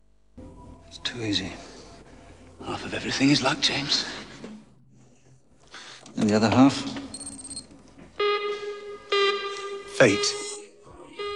fate [vocals].wav